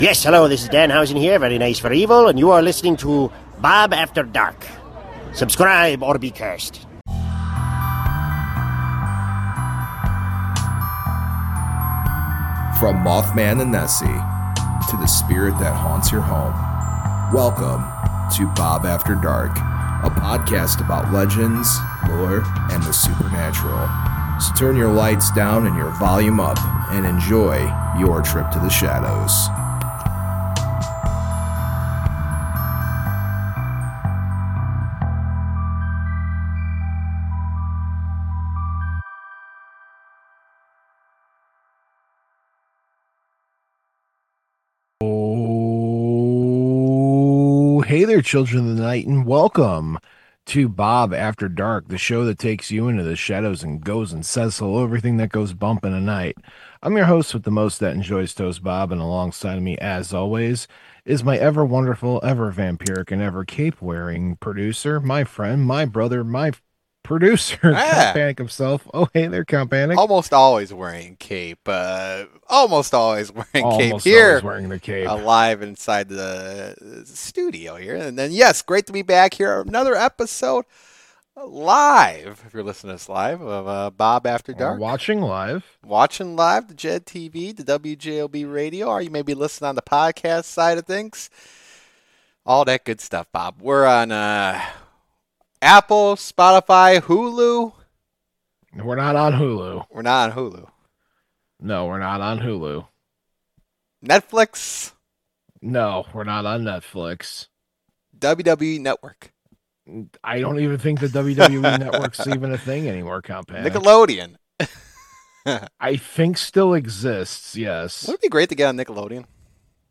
Join the Bob After Dark team as they discuss some of those things that go bump in the night.